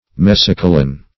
Mesocolon \Mes`o*co"lon\, n. [NL., fr. Gr. meso`kwlon; me`sos